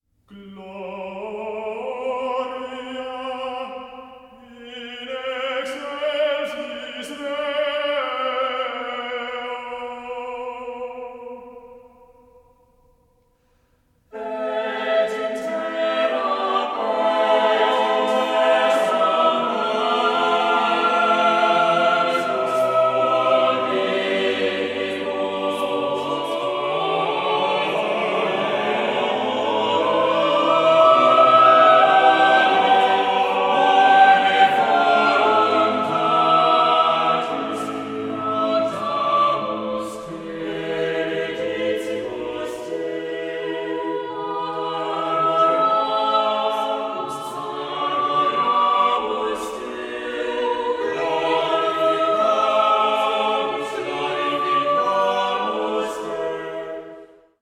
24-bit stereo